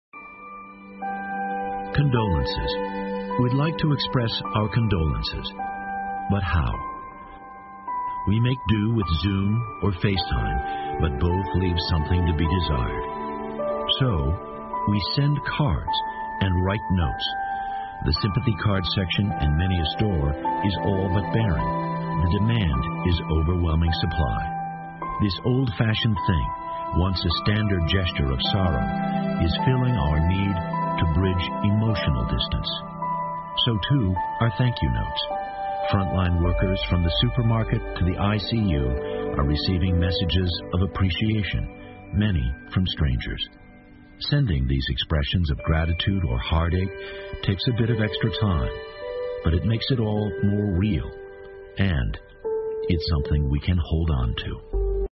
NBC晚间新闻 一线人员收到大量感谢信 听力文件下载—在线英语听力室